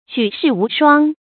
注音：ㄐㄨˇ ㄕㄧˋ ㄨˊ ㄕㄨㄤ
舉世無雙的讀法